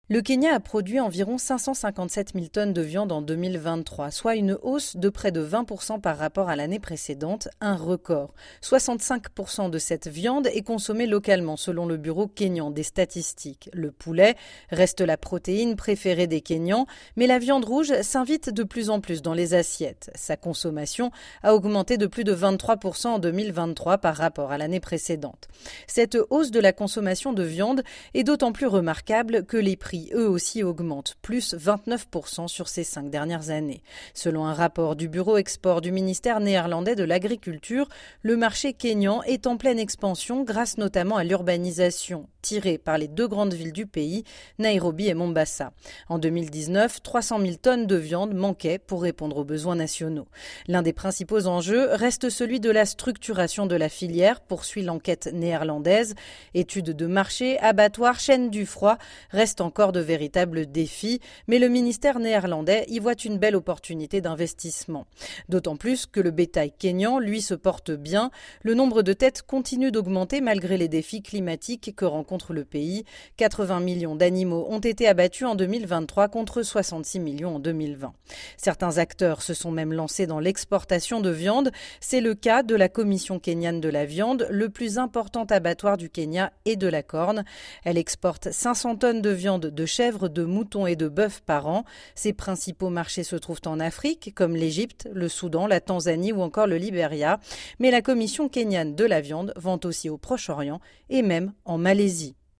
Selon une étude publiée récemment par le Bureau kényan des statistiques, la viande a généré près de 304 milliards de shillings kényans, soit environ 225 millions d’euros en 2023. La consommation de viande ne cesse d’augmenter, bien que les prix soient en hausse. De notre correspondante à Nairobi, Le Kenya a produit environ 557 000 tonnes de viande …